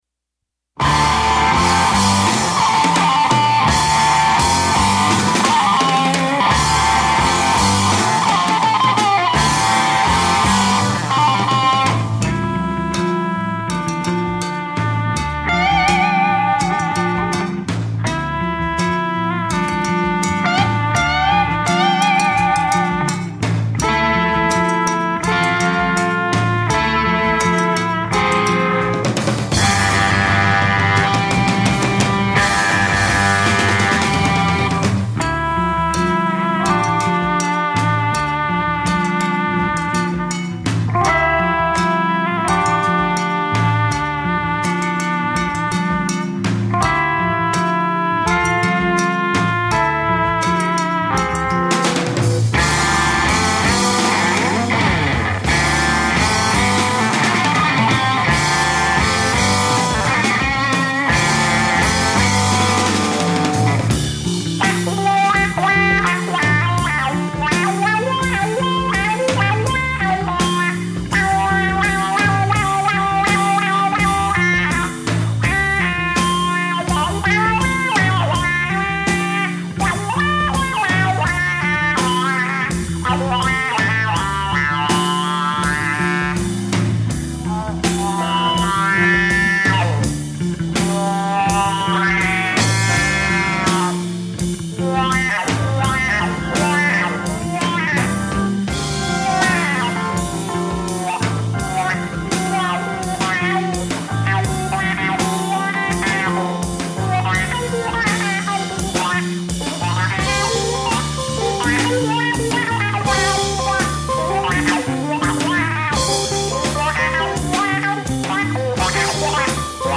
Today’s explorations will lean toward jazz.